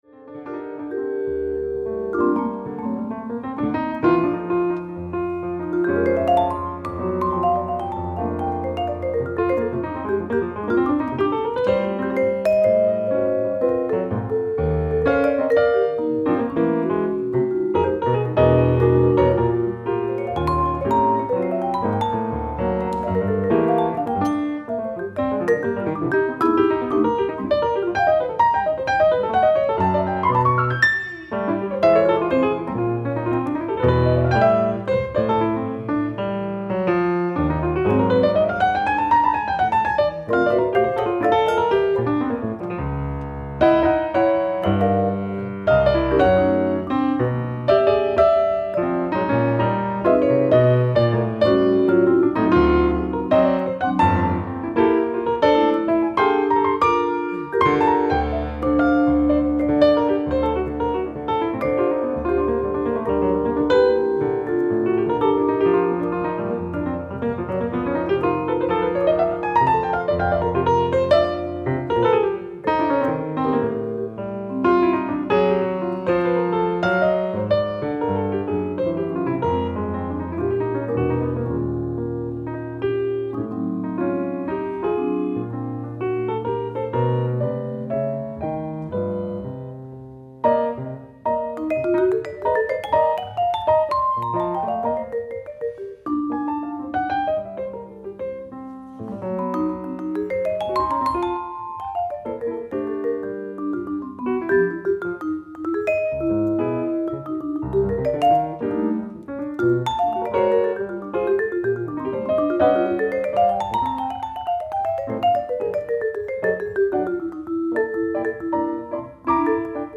ライブ・アット・ハンブルグ、ドイツ
※試聴用に実際より音質を落としています。